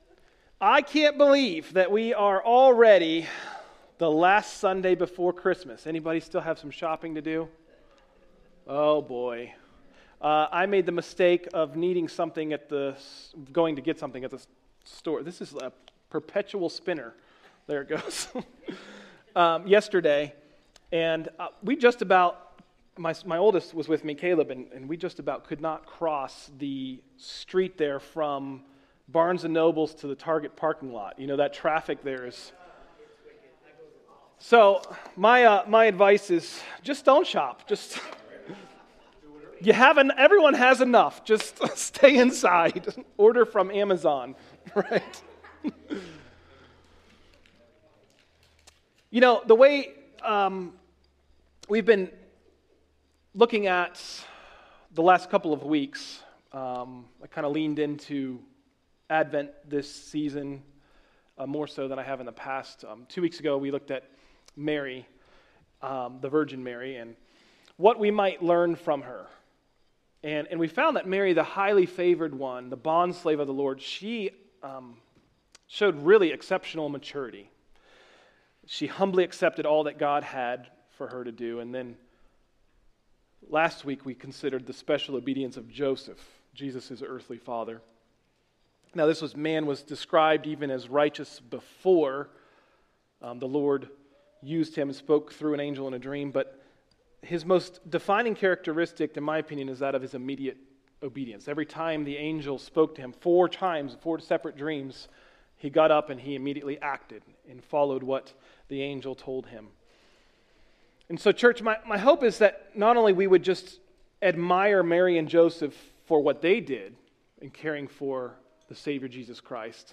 Message: “A ‘Christ’mas Message” – Tried Stone Christian Center